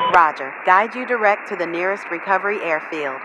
Radio-atcRequireNearestAirbase3.ogg